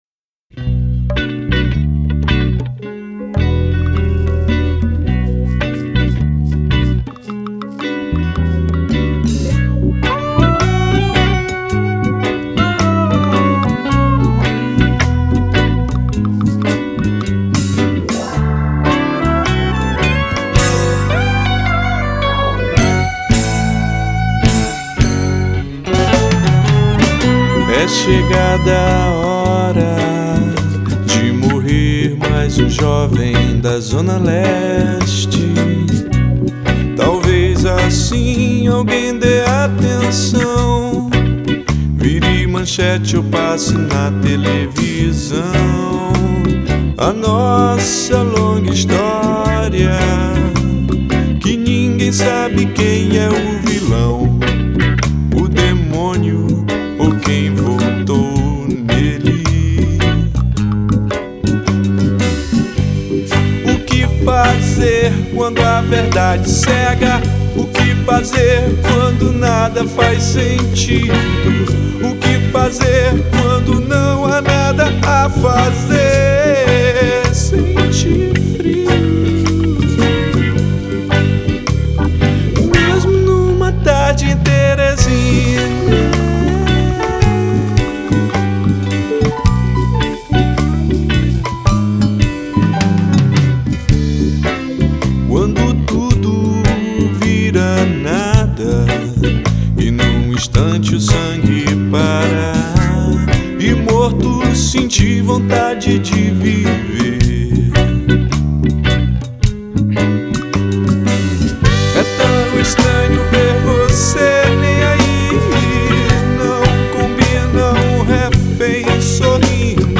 2713   03:41:00   Faixa: 8    Rock Nacional